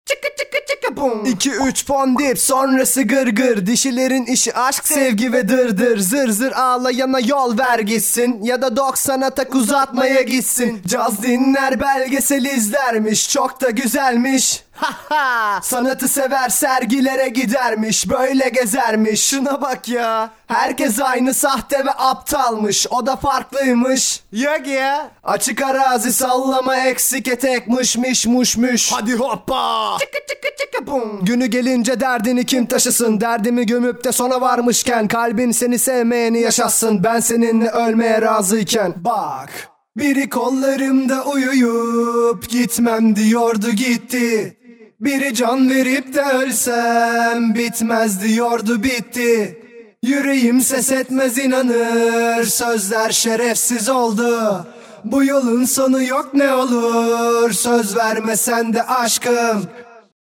norm ender imaj seslendirme castına bağlı dublaj sanatçısıdır. Çeşitli reklamlarda seslendirme yapmıştır.